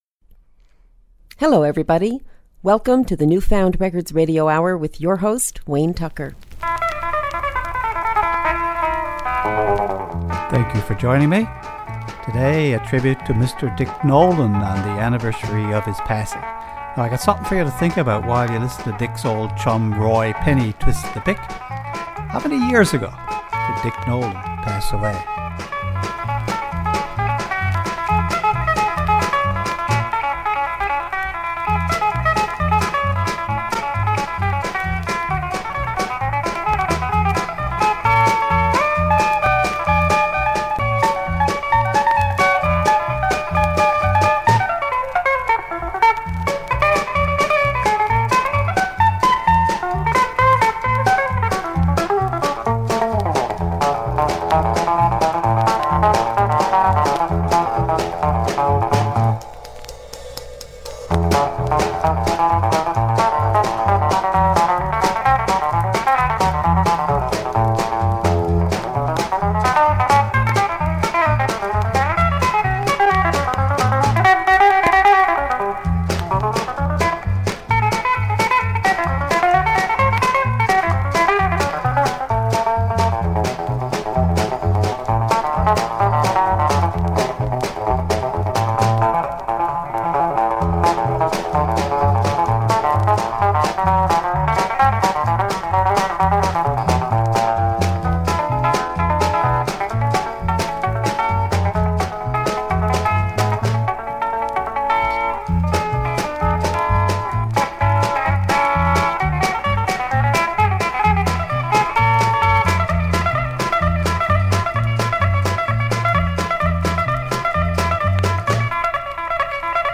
Recorded at CHMR studios, MUN, St. John's, NL.